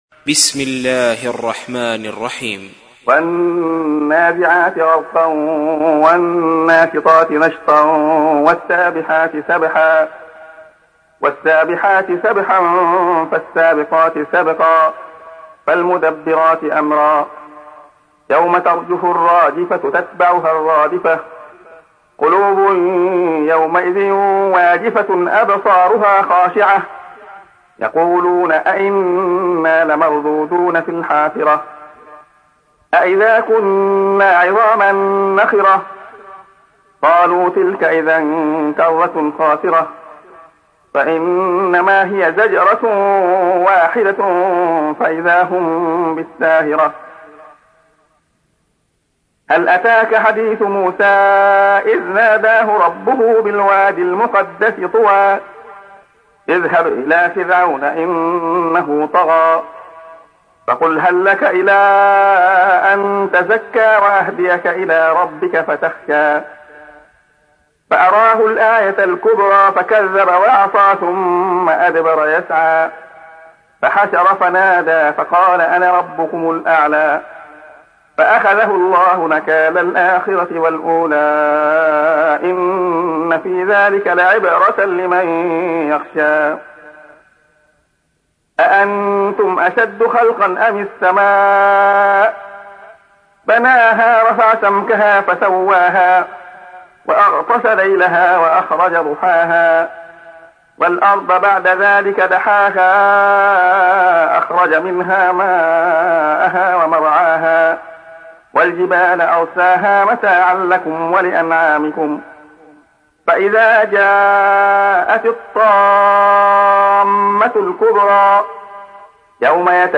تحميل : 79. سورة النازعات / القارئ عبد الله خياط / القرآن الكريم / موقع يا حسين